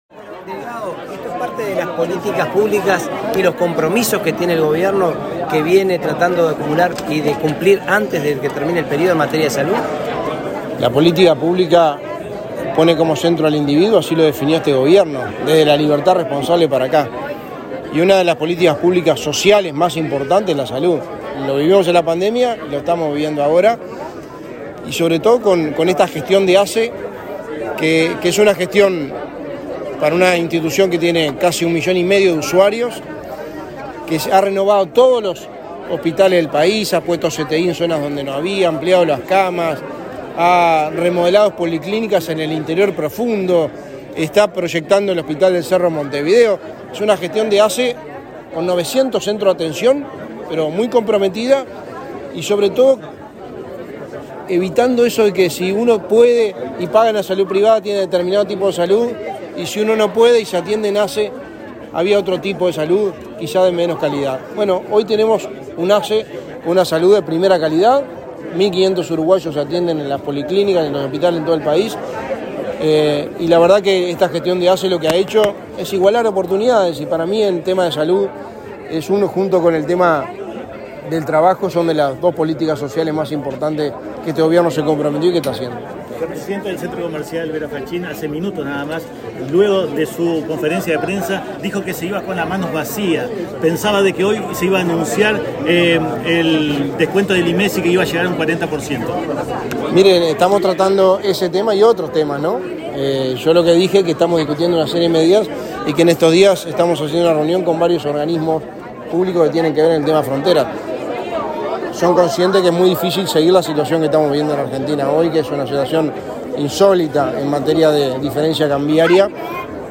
Declaraciones del secretario de Presidencia, Álvaro Delgado
En el marco de una gira por el departamento de Salto, el secretario de Presidencia, Álvaro Delgado, participó en la inauguración de una unidad básica